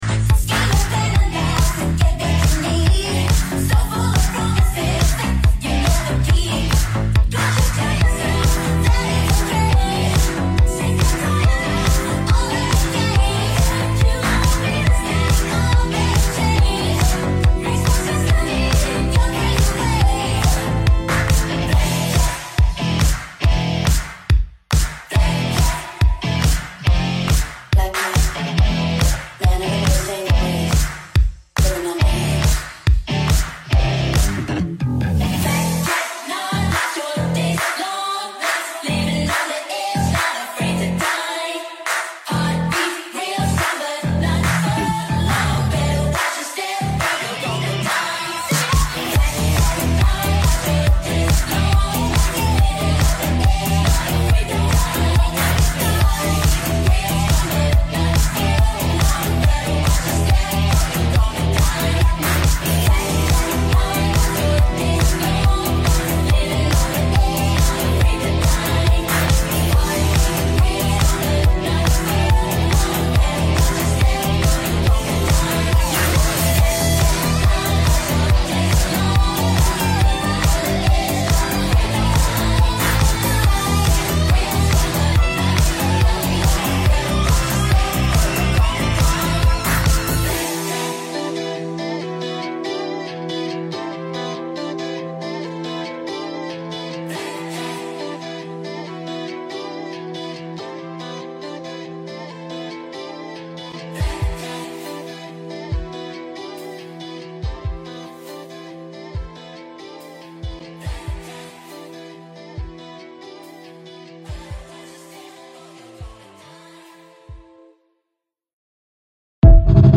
From late-night get-ins to full-on tech rehearsals, the Cobra Lighting team has been hard at work designing, programming, and operating all the lighting for this year’s incredible Clique College End of Year Showcase at The Great Hall, Canterbury.